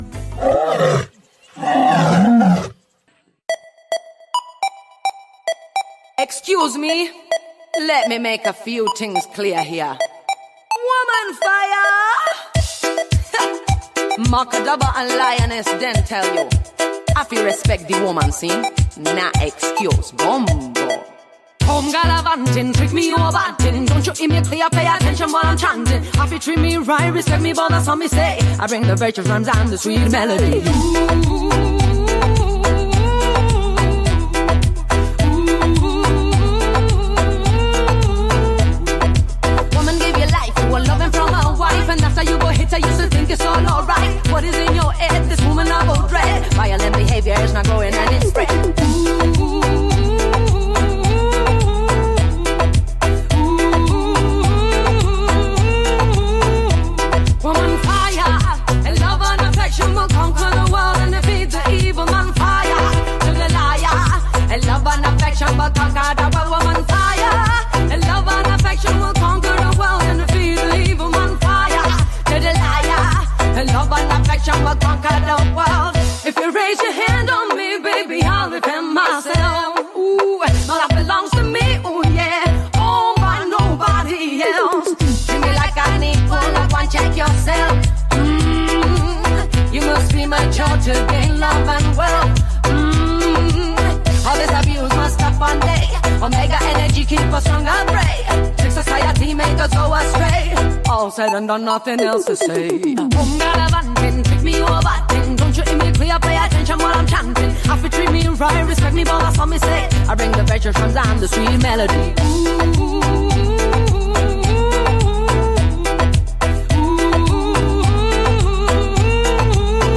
cantante del mondo Reggae proveniente da Tenerife, Isole Canarie.